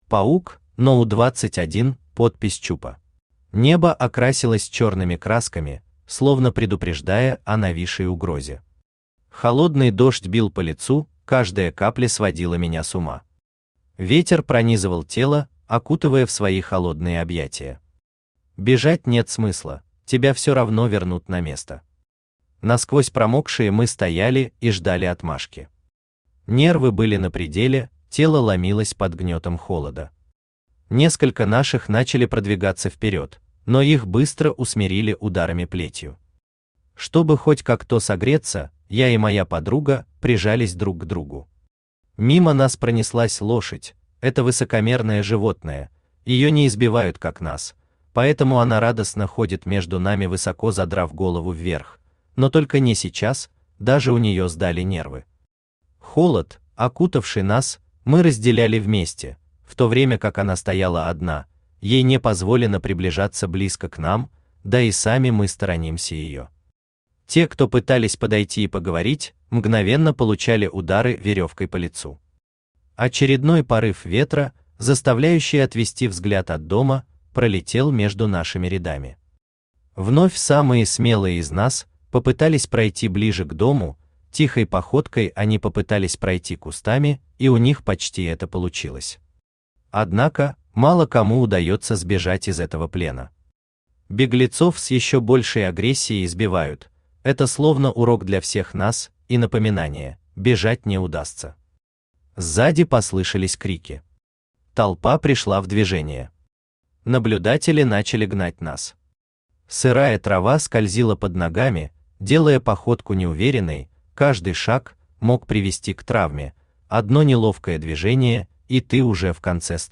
Аудиокнига №21 подпись чупа | Библиотека аудиокниг
Aудиокнига №21 подпись чупа Автор Паук Читает аудиокнигу Авточтец ЛитРес.